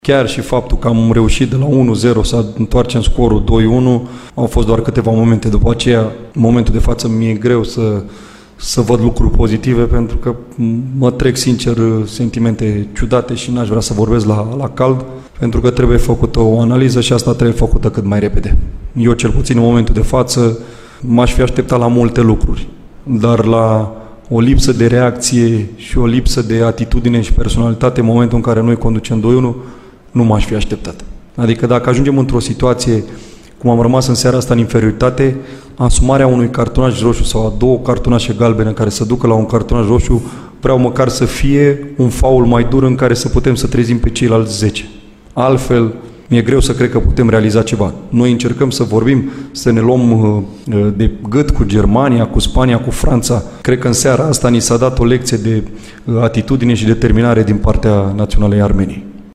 La final, selecționerul Mirel Rădoi a declarat că aspecte pozitive după un astfel de joc nu există și a lăsat să se înțeleagă că se gândește serios la viitorul său în acest post: